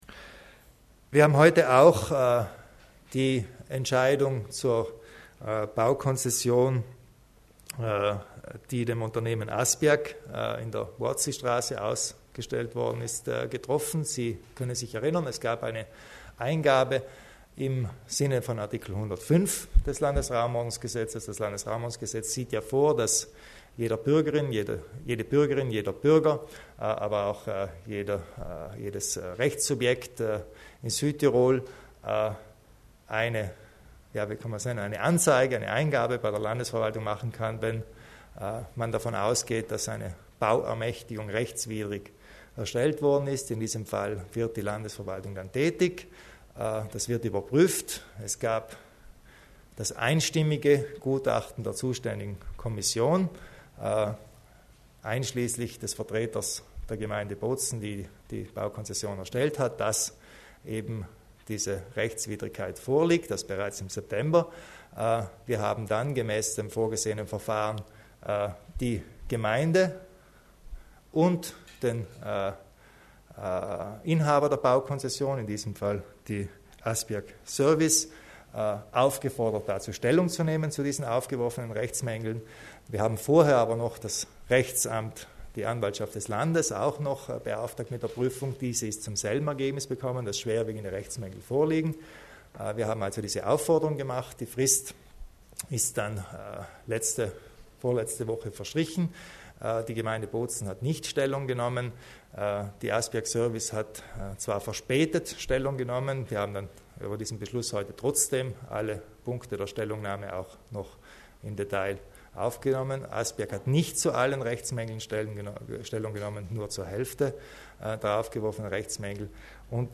Landeshauptmann Kompatscher erläutert die Annullierung der Baukonzession für das Unternehmen ASPIAG